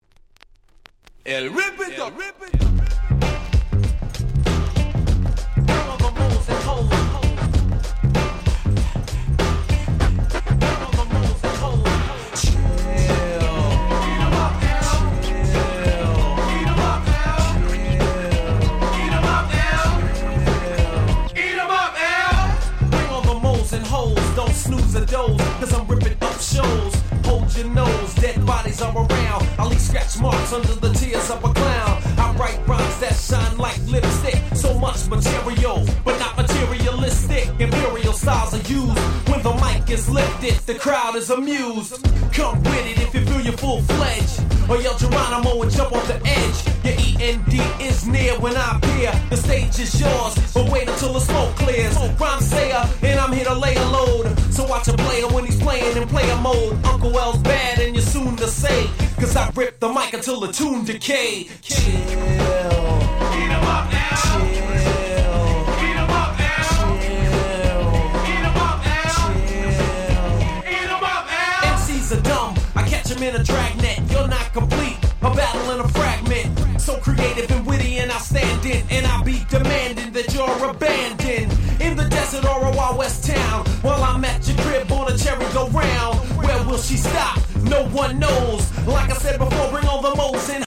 91' Super Hip Hop Classics !!
90'sHip Hopが好きなら200%この曲はマストです。